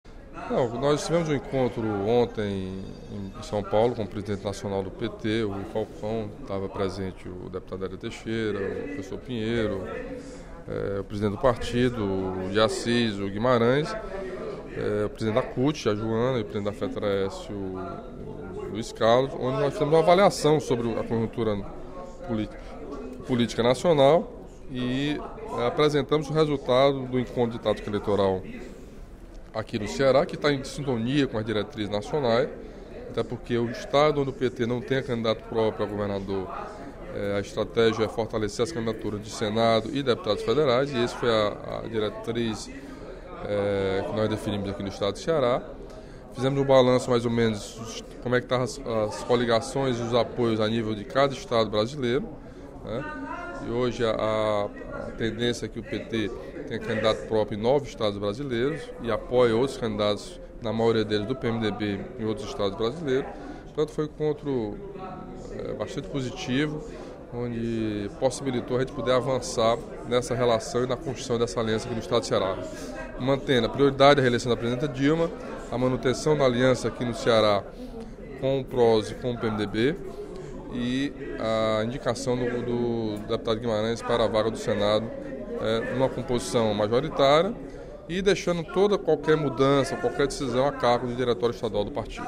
No primeiro expediente da sessão plenária desta quarta-feira (09/04), o deputado Camilo Santana (PT) destacou o encontro realizado pelo Partido dos Trabalhadores, ontem, em São Paulo, com a presença das principais lideranças.